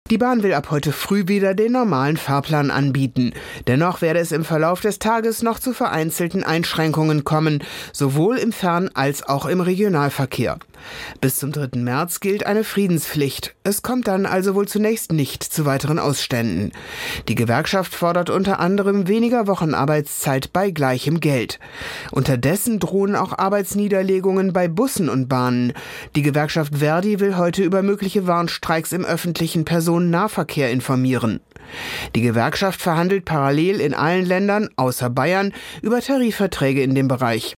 Nachrichten GDL beendet Streik vorzeitig